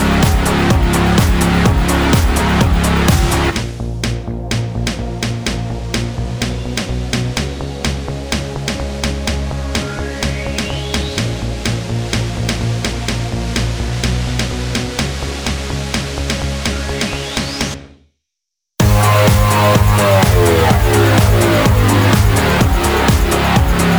Minus Main Guitar Pop (2010s) 3:54 Buy £1.50